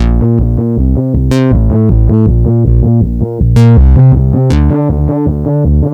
bass1.aiff